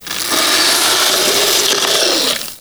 MONSTER_Noise_02_mono.wav